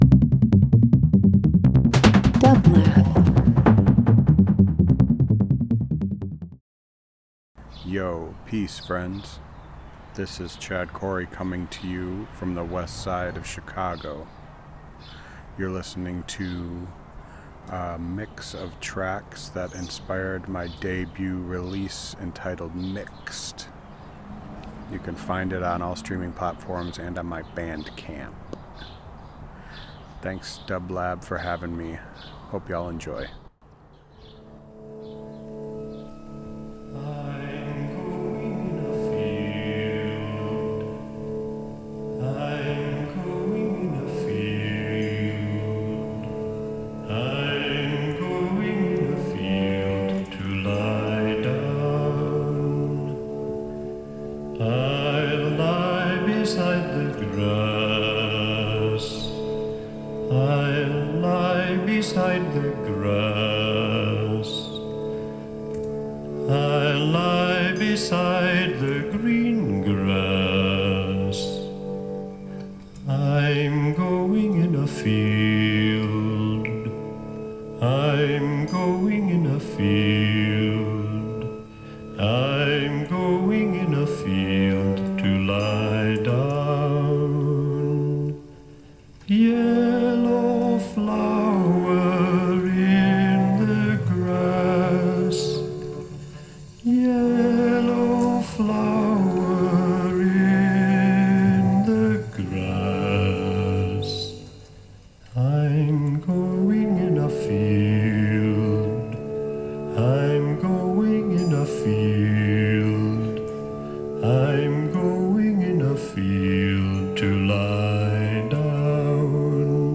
Experimental Instrumental Jazz